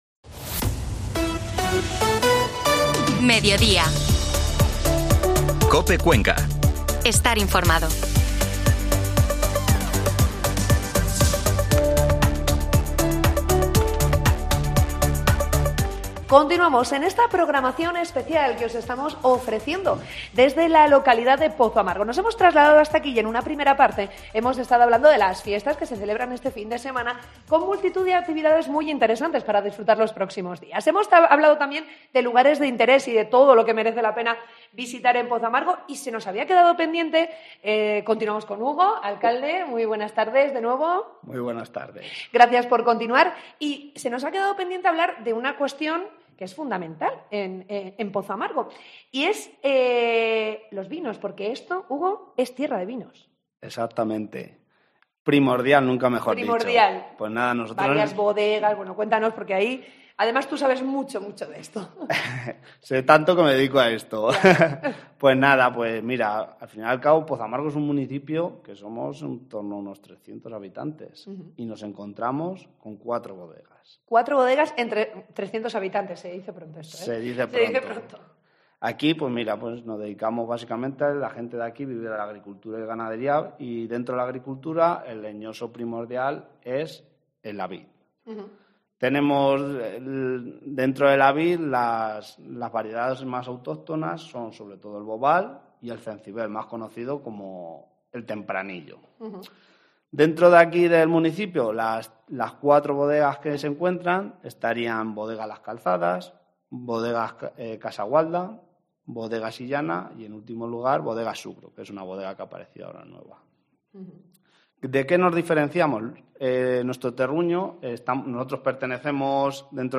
AUDIO: Programa especial en COPE Cuenca desde Pozoamargo